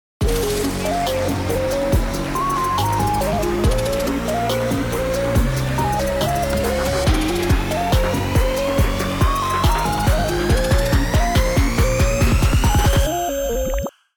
EDMやダンスミュージックで欠かせないFXサウンド、RiserとUplifterは盛り上がりを演出するのに欠かせない定番のサウンドです。
▼RiserとUplifter サウンドサンプル
後半にかけてより上昇感が強まるよう曲線を描くことがポイントです。
さらに盛り上がりを演出するためノイズのサウンドを追加します。
仕上げに「Reverb」の「TIME」ノブを調整し、長めの余韻を作り出せばサウンドの完成です。
Riser_Sound.mp3